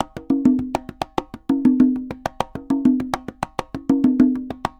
Congas_Candombe 100_1.wav